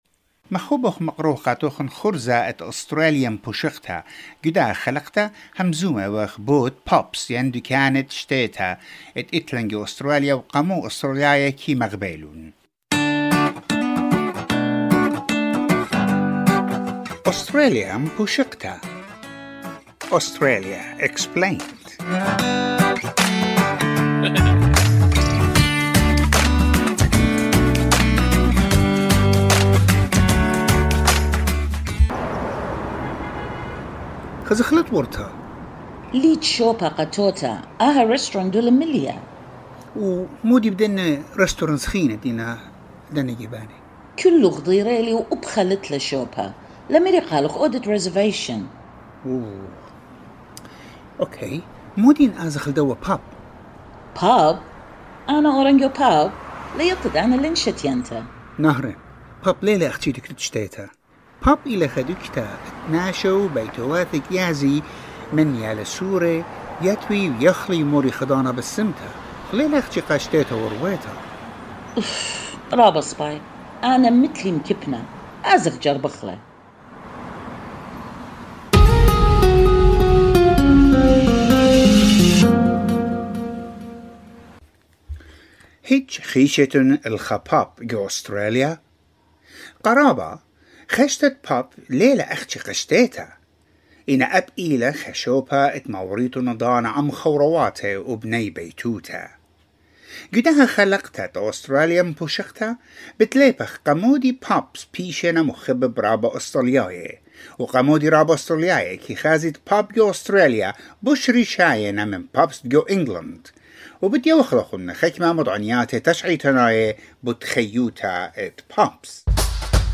والتقى ميكروفون أس بي أس بعينة من أهالي الطلاب العائدين الى المدرسة في اليوم الأول لهذا العام، ليسألهم عن شعورهم وعن مخاوفهم وقلقهم ويستطلع آراءهم حول الإستعدادات والتحضيرات والإجراءات الوقائية المحيطة المعمول بها لكبح جماح الفيروس.